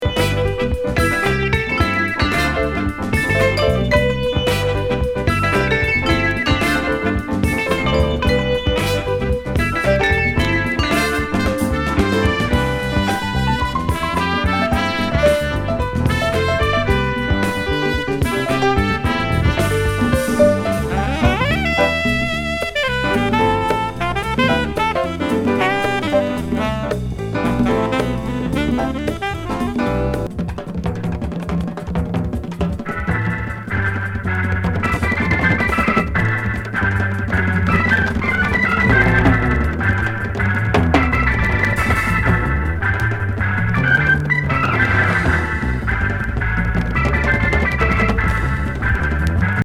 NY80年録音
メロウ・レゲー・フュージョン